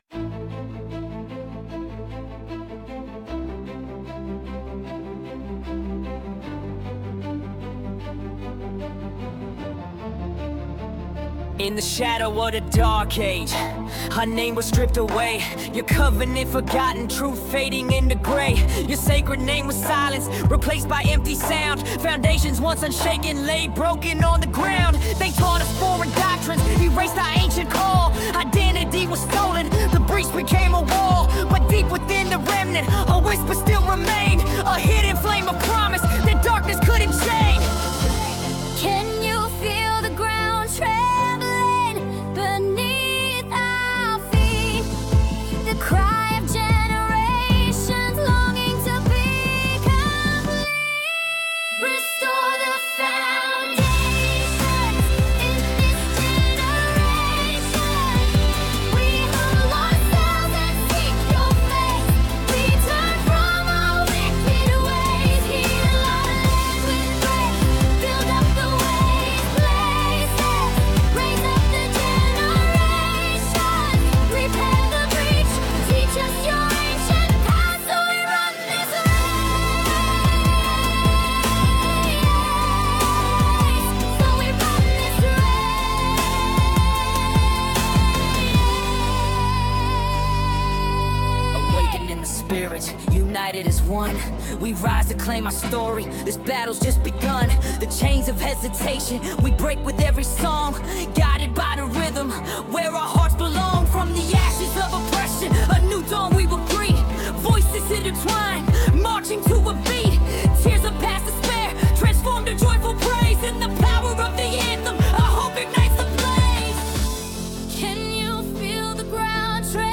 Original worship music including
• [ Practice Track – Higher Key ]